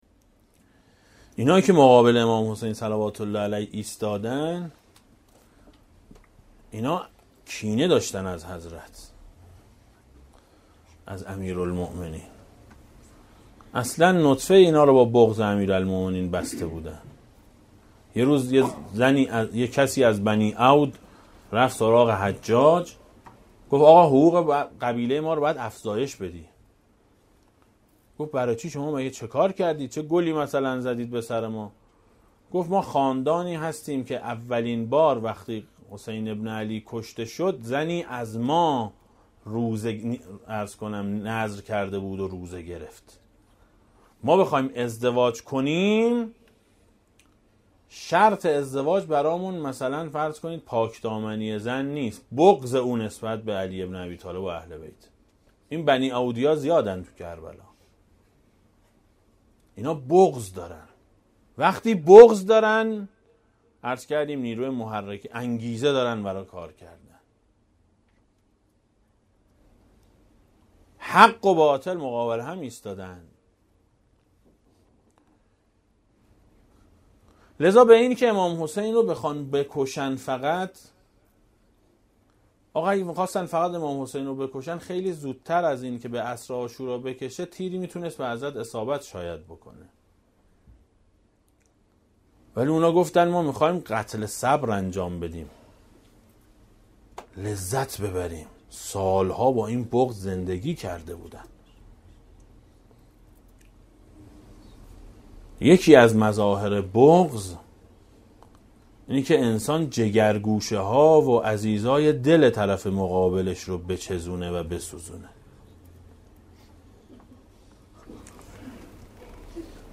روضه شب سوم محرم سال 1395 ـ مجلس اول